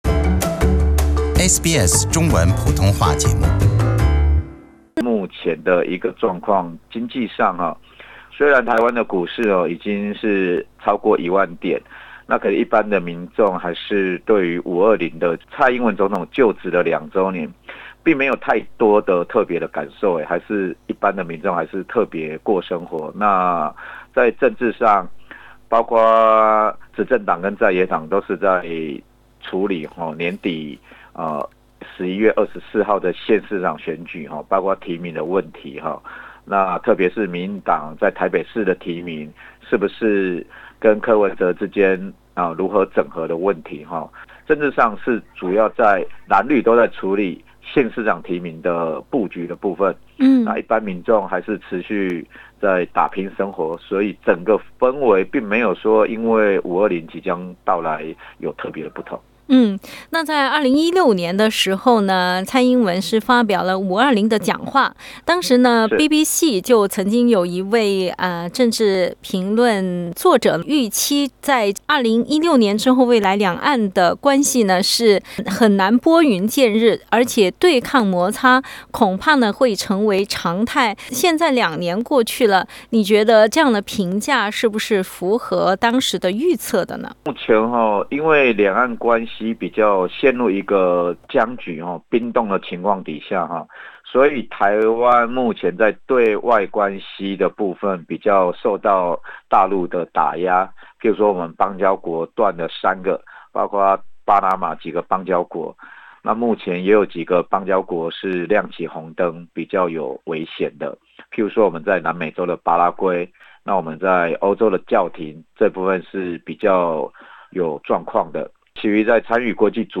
本节目为嘉宾观点，不代表本台立场。